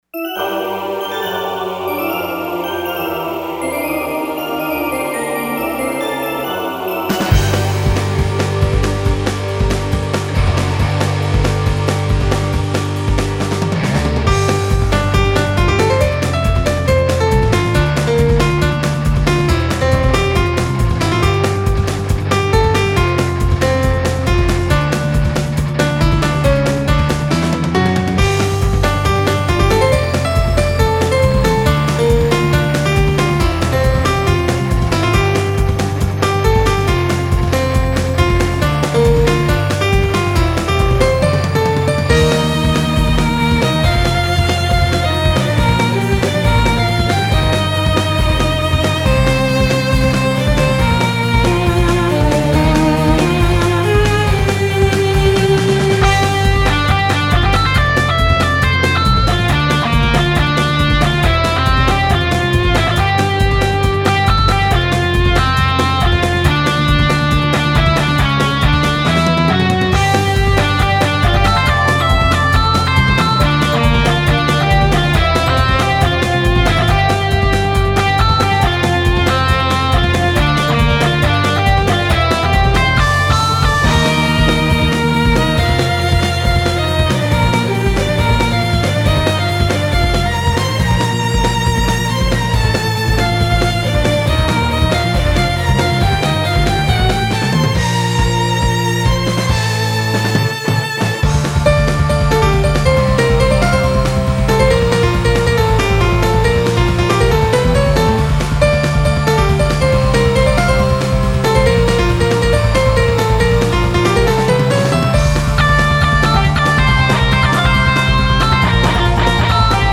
フリーBGM フィールド・ダンジョン フィールド探索・疾走感